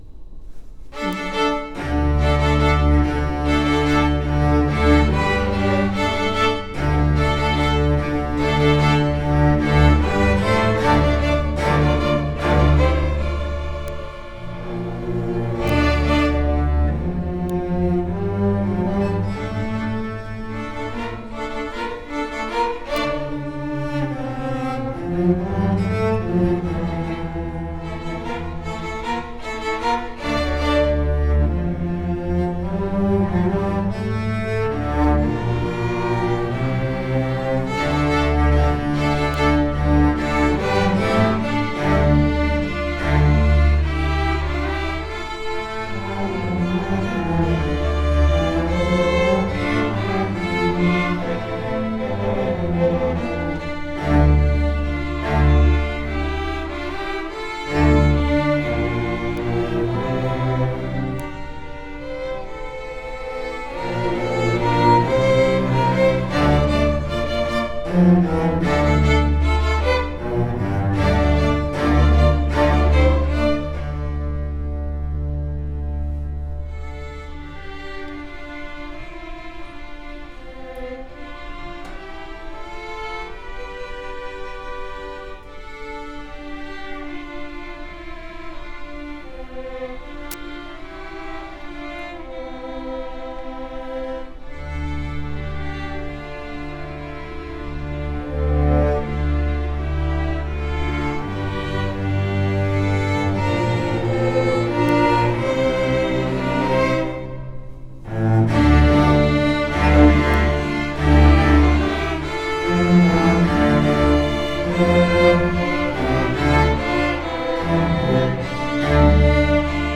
Orchestra District MPA 2020 – March 10 – 12 at Largo High School